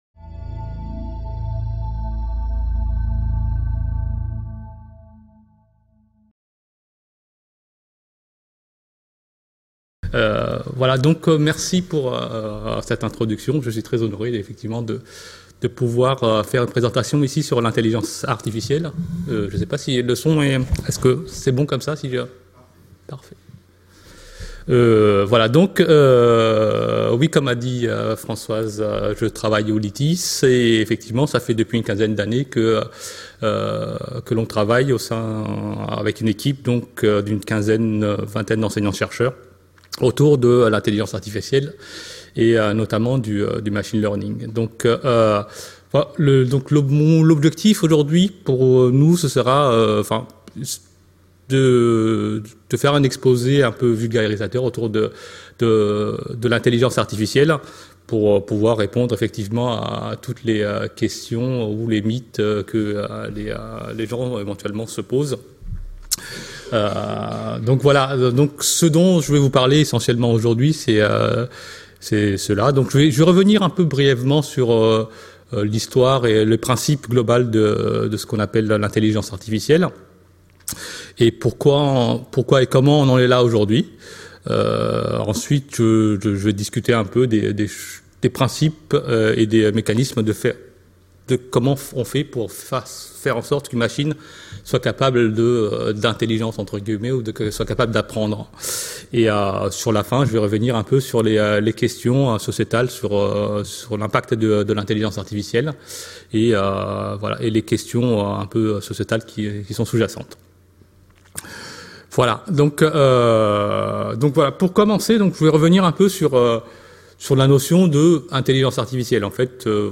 L’objectif de cette présentation est de le rendre accessible à un plus large public.